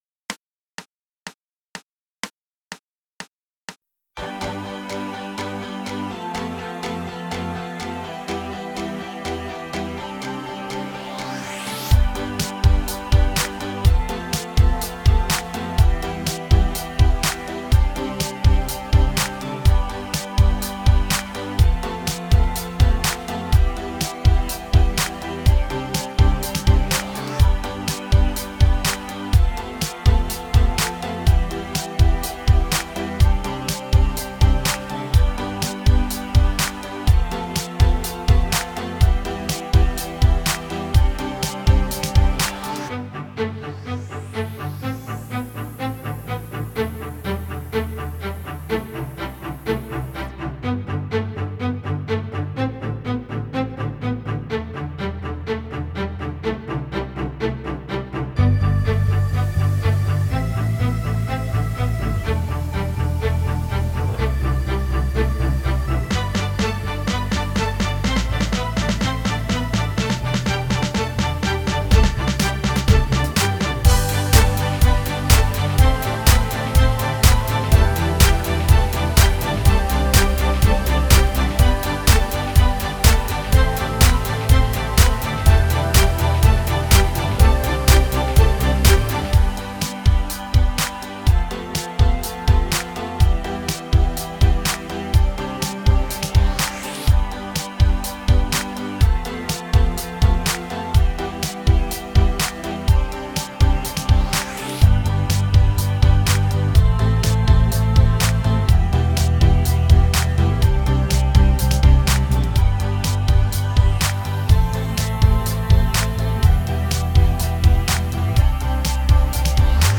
Here's the backing track which everyone will play/sing to:
Backing Track